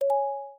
Index of /phonetones/unzipped/Samsung/Z3/sounds/mm-camcorder
recording_stop_01.ogg